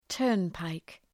Προφορά
{‘tɜ:rn,paık}